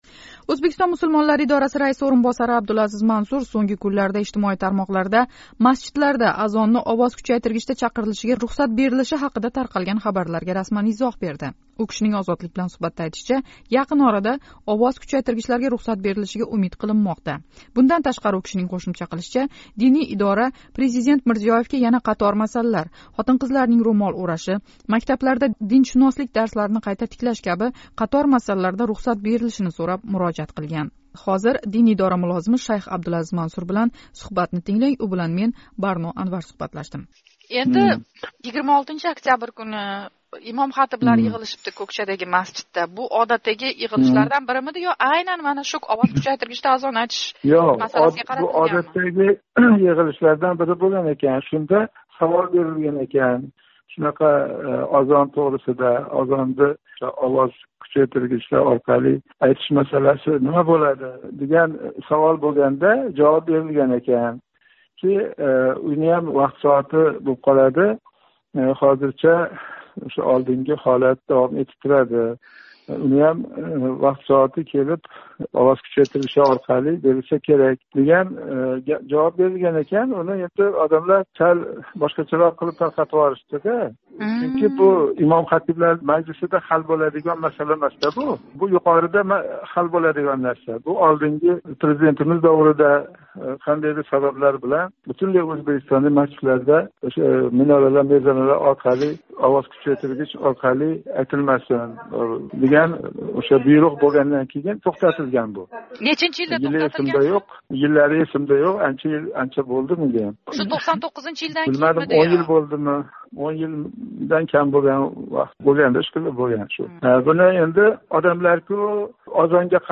Қуйида диний идора мулозими билан суҳбатни тўлиқ тинглашингиз мумкин: